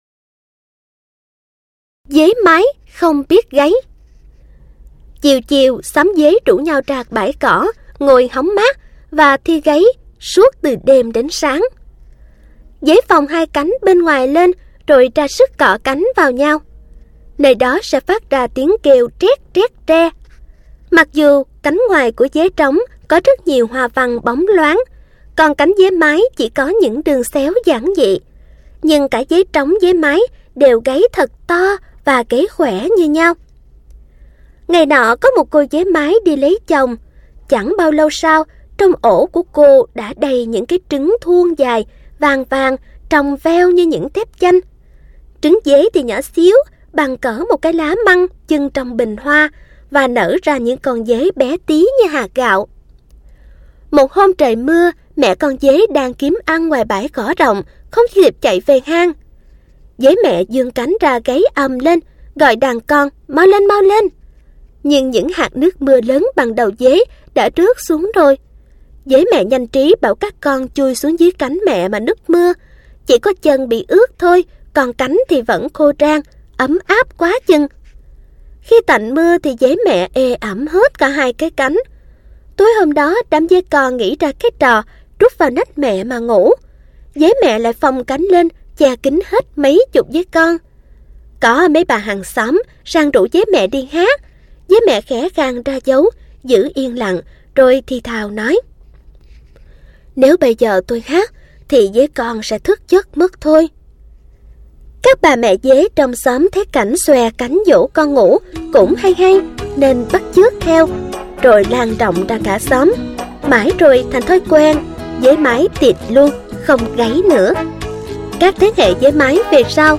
Sách nói | Xóm Đồ Chơi P26